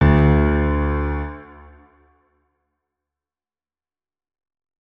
piano